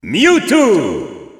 Announcer pronouncing Mewtwo in French.
Mewtwo_French_Announcer_SSBU.wav